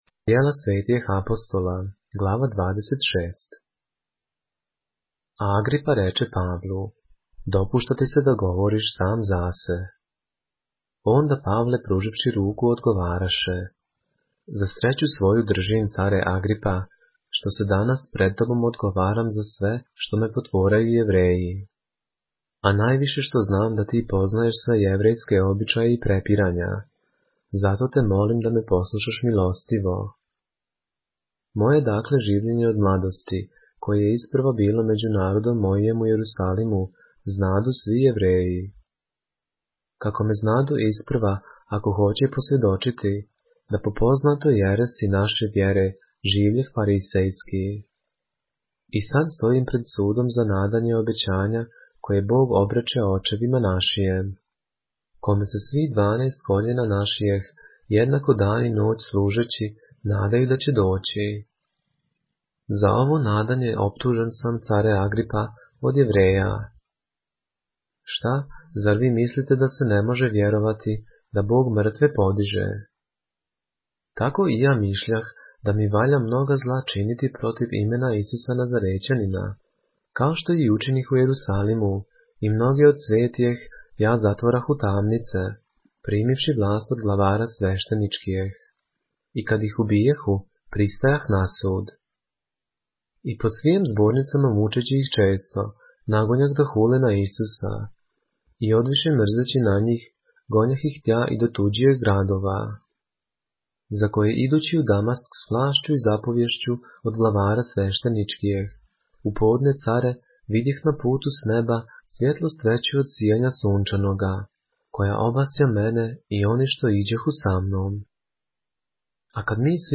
поглавље српске Библије - са аудио нарације - Acts, chapter 26 of the Holy Bible in the Serbian language